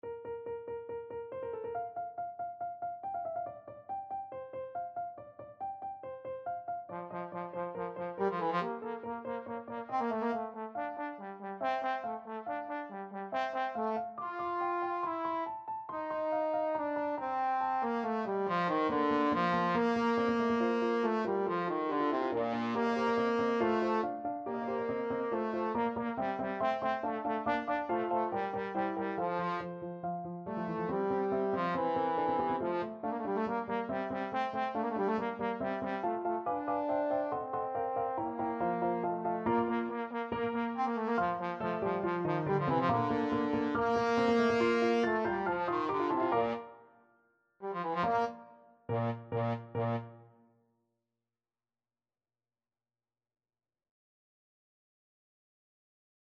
Classical Mozart, Wolfgang Amadeus Magic Flute Overture Trombone version
Trombone
= 140 Allegro (View more music marked Allegro)
Bb major (Sounding Pitch) (View more Bb major Music for Trombone )
4/4 (View more 4/4 Music)
Classical (View more Classical Trombone Music)
MagicFluteOverture_TBNE.mp3